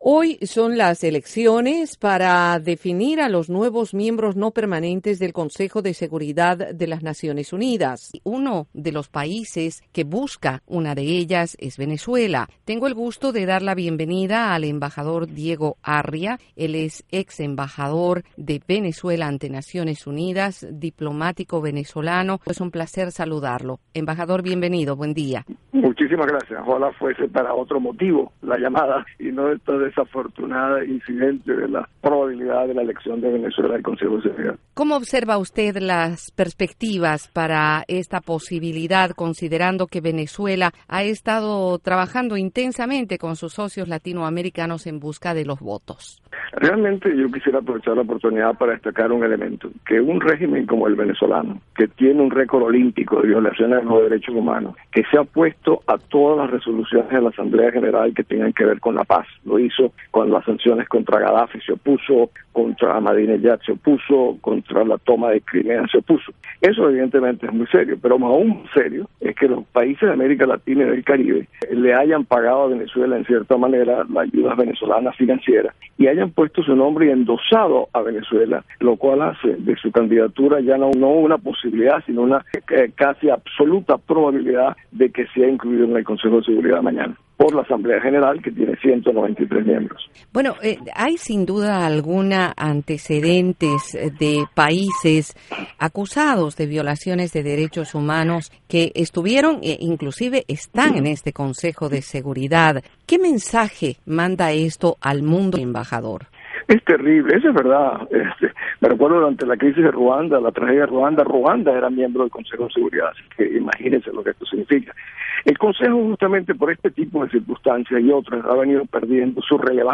Entrevista al embajador Diego Arria